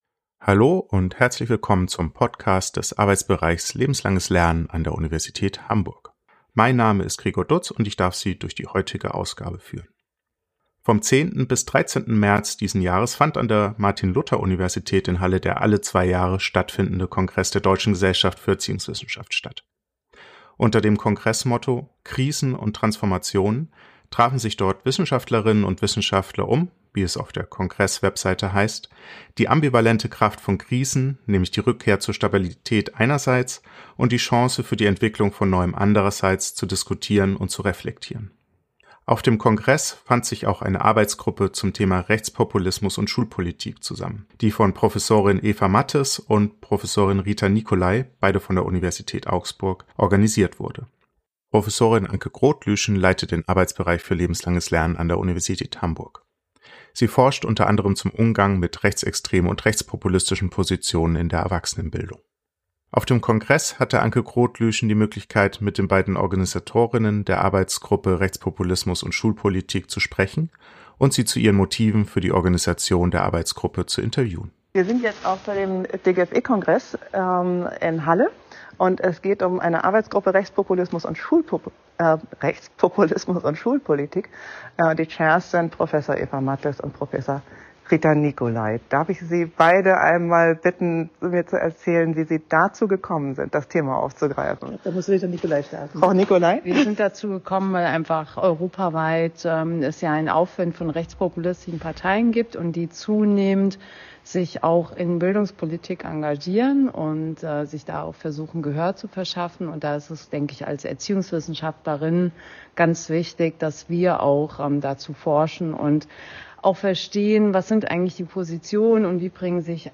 Beschreibung vor 1 Jahr Vom 10. bis 13. März 2024 fand an der Martin-Luther-Universität in Halle der alle zwei Jahre stattfindende Kongress der Deutschen Gesellschaft für Erziehungswissenschaft statt.
Im Anschluss an dieses Interview hören Sie die von uns aufgezeichnete Einführung in die Arbeitsgruppe sowie kurze Zusammenfassungen der dort gehaltenen Vorträge. Die Vorträge der Arbeitsgruppe befassen sich mit den schulpolitischen Positionen der AfD, rechtspopulistischen Ansichten zur Sexualerziehung und den Gemeinsamkeiten zwischen rechten christlichen Gruppen und der AfD am Beispiel der Debatte um den Bildungsplan in Baden-Württemberg.